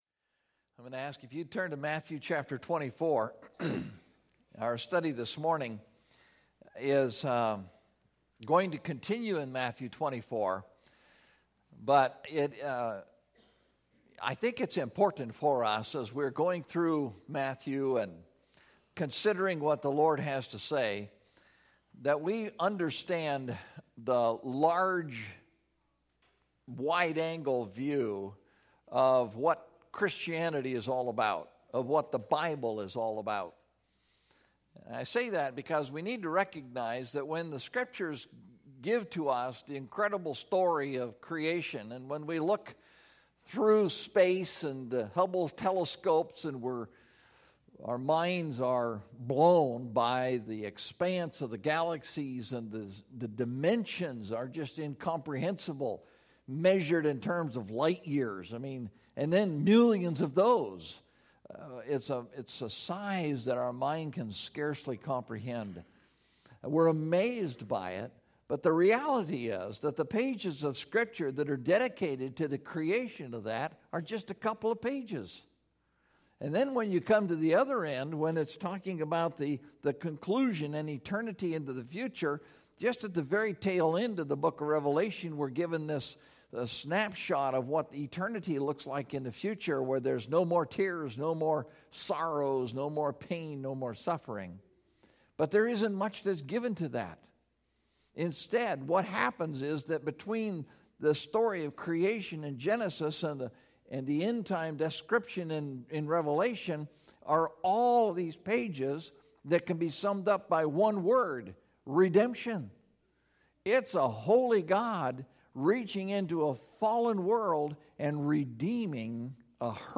The Sign Of The Son Of Man (Matthew 24:29-31) – Mountain View Baptist Church